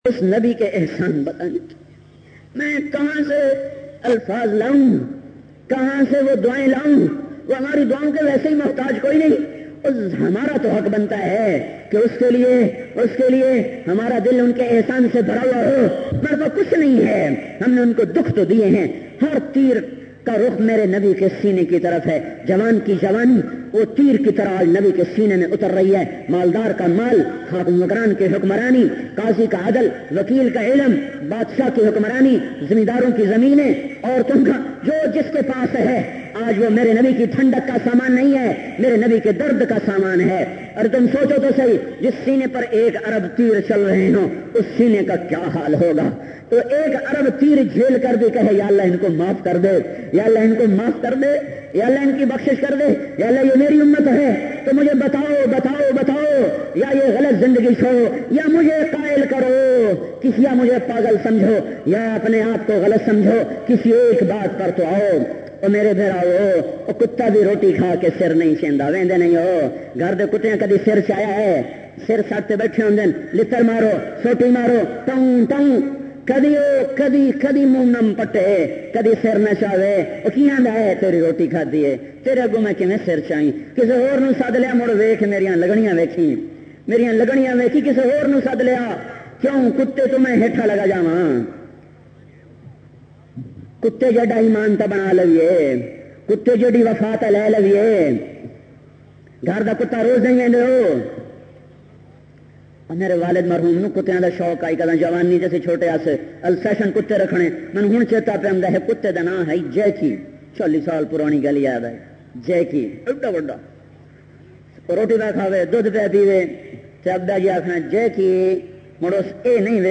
Bay His Insan bayan mp3